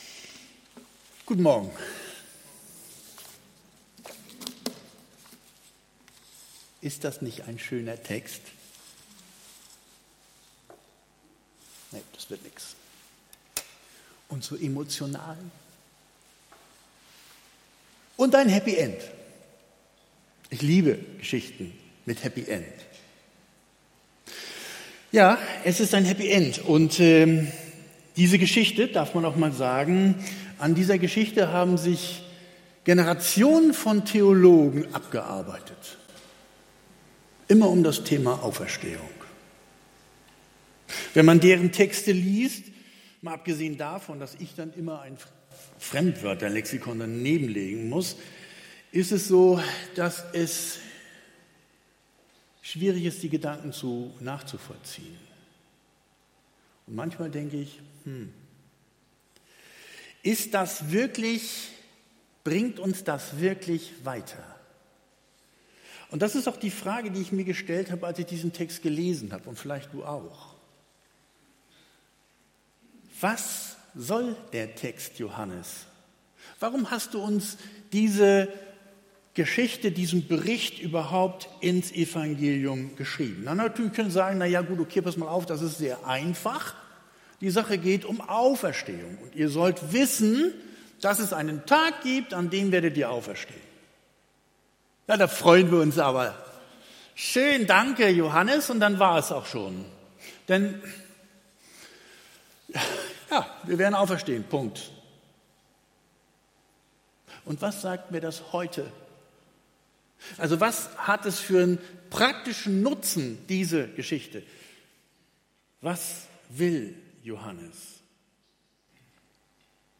Predigt vom 05.03.2023